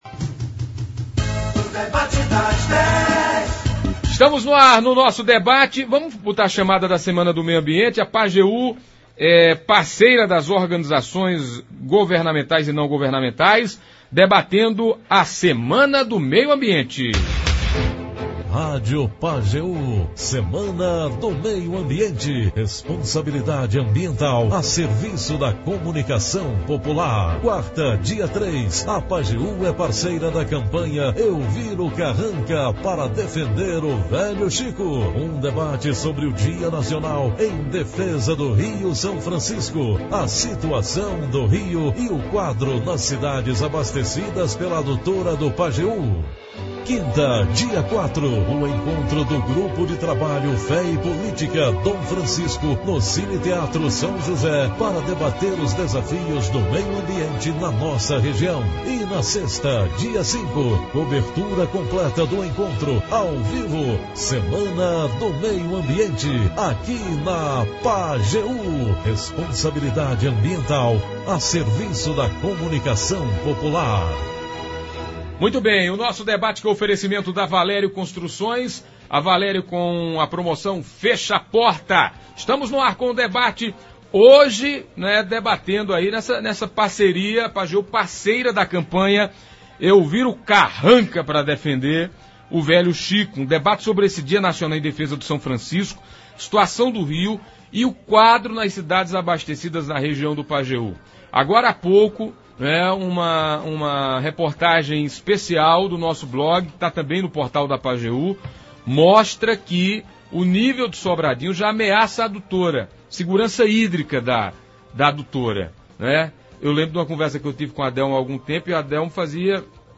Esta semana a Pajeú está com programação especial voltada para a Semana do Meio Ambiente.